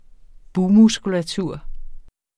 Udtale [ ˈbuː- ]